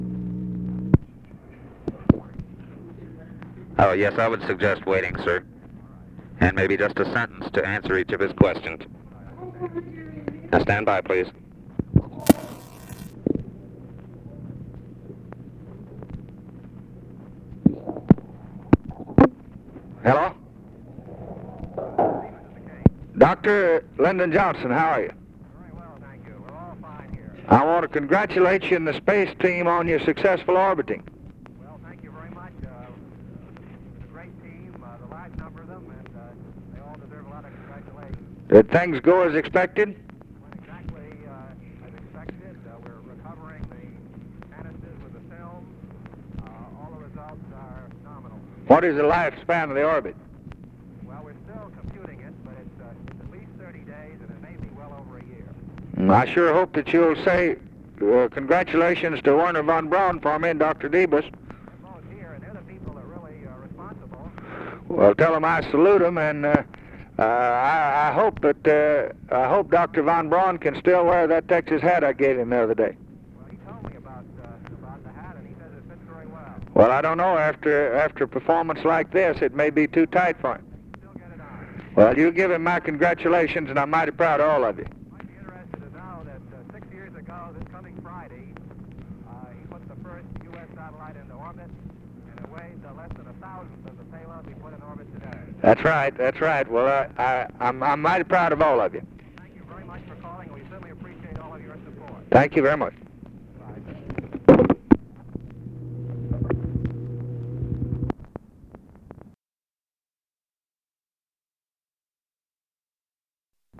Conversation with UNIDENTIFIED MALE, January 29, 1964